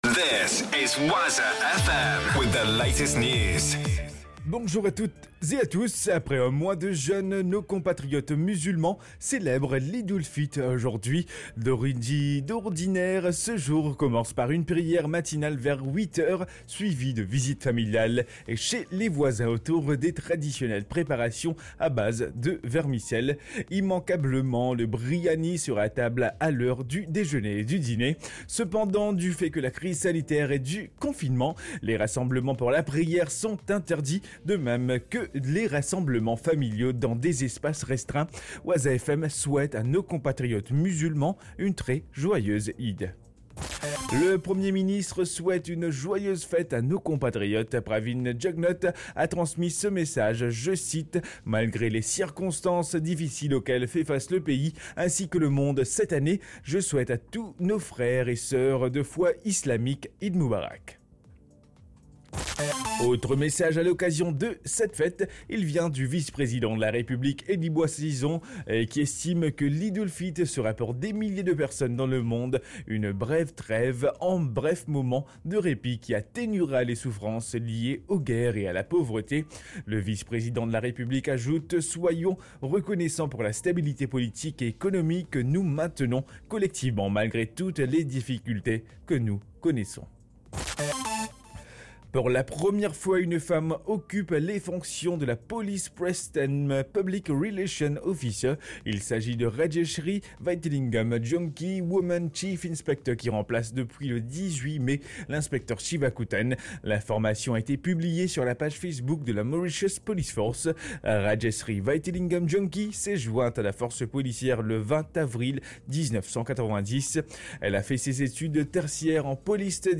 NEWS 7HR-SUNDAY 24.05.2020